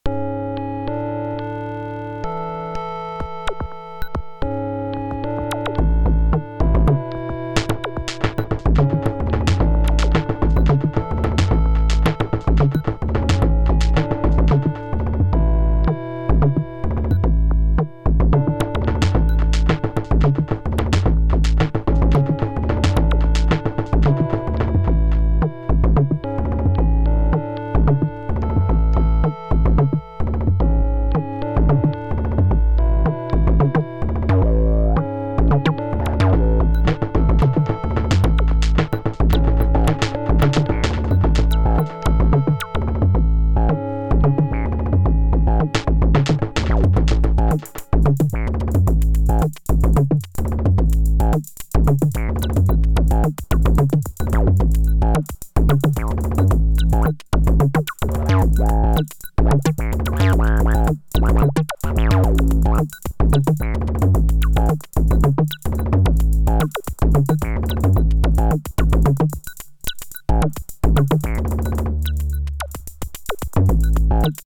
Kind of a weird beat that turned into something else…